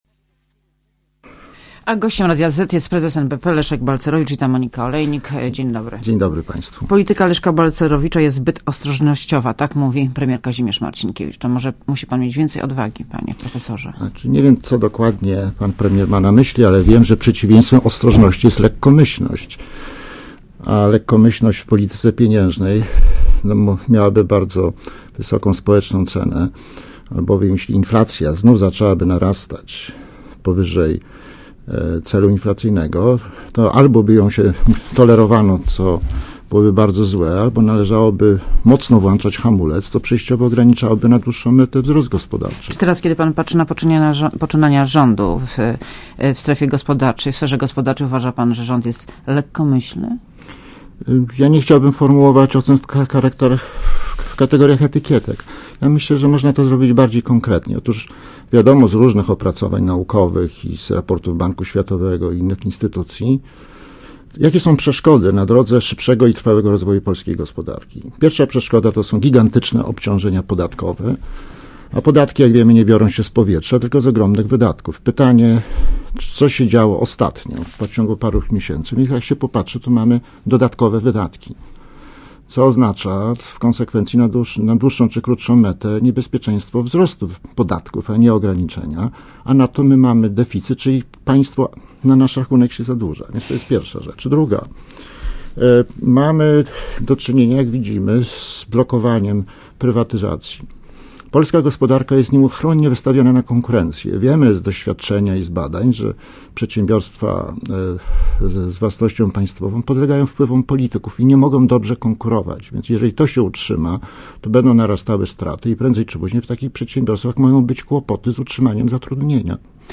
Posłuchaj wywiadu Gościem Radia ZET jest Leszek Balcerowicz , prezes NBP, Witam, Dzień dobry.